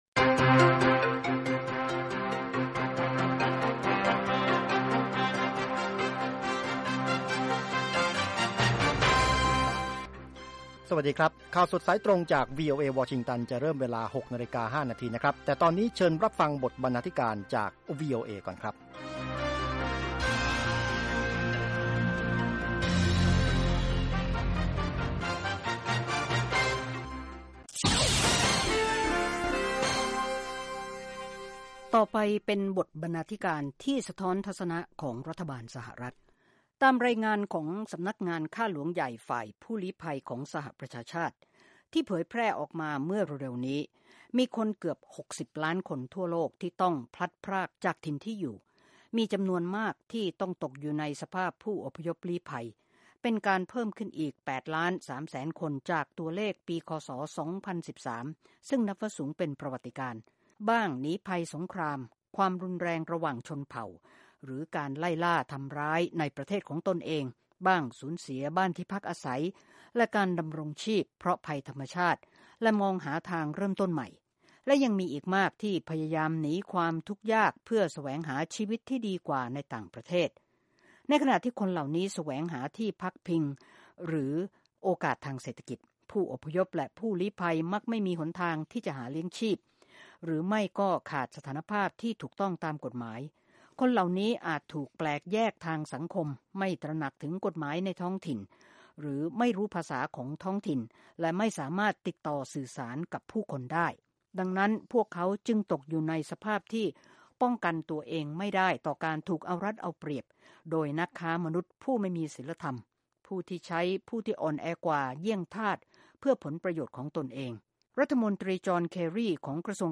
ข่าวสดสายตรงจากวีโอเอ ภาคภาษาไทย 6:00 – 6:30 น วันจันทร์ 3 ส.ค. 2558